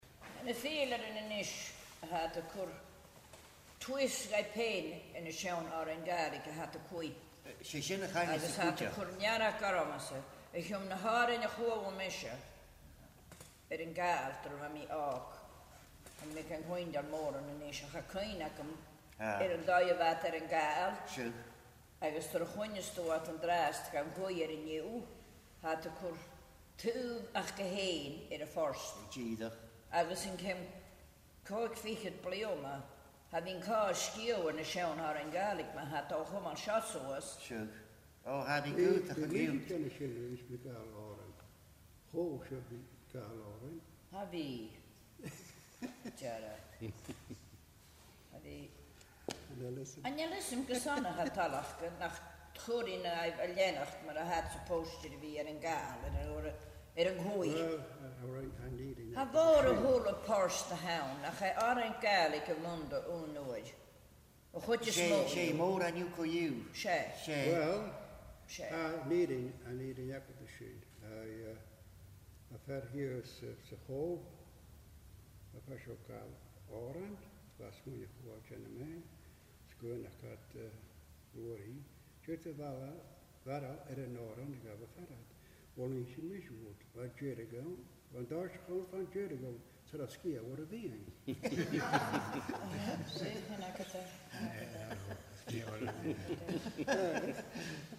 Glenora Distillery, An Gleann Dubh
Agallamh